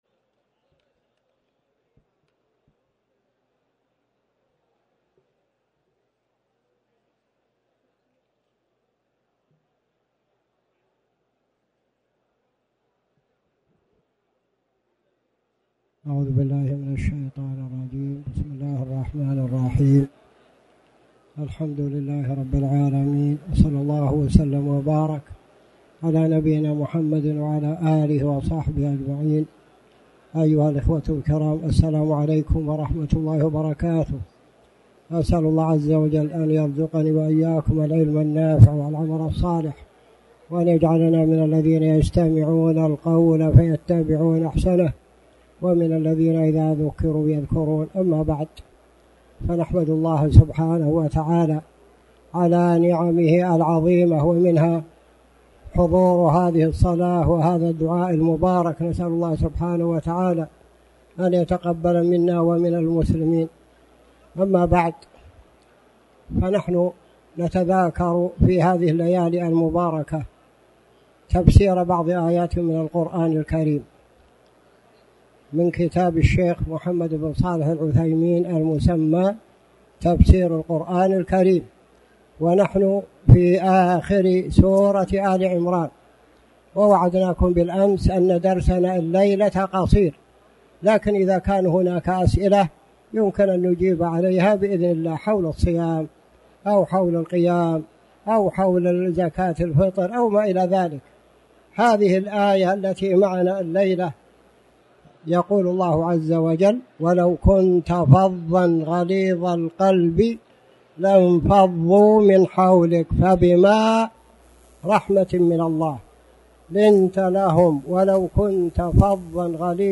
تاريخ النشر ٢٨ رمضان ١٤٣٩ هـ المكان: المسجد الحرام الشيخ